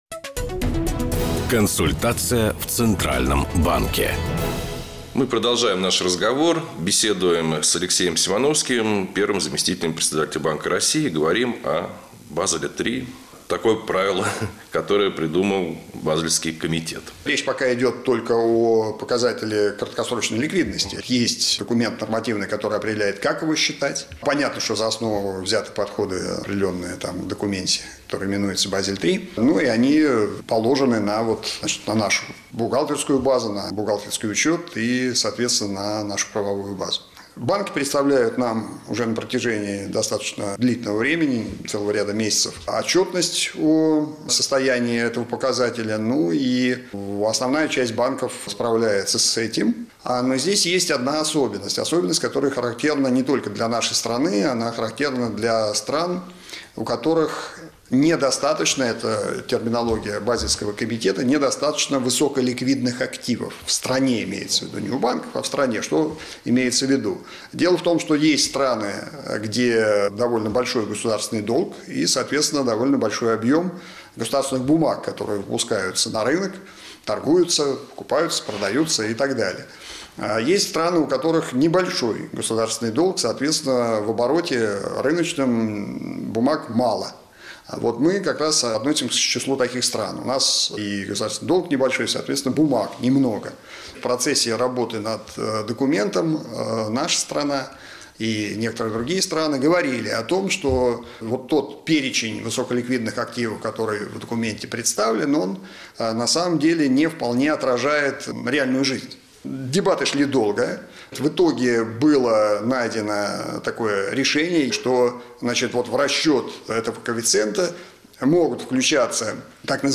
Интервью
Интервью первого заместителя Председателя Банка России А.Ю. Симановского радиостанции «Бизнес ФМ» 31 октября 2014 года (часть 2)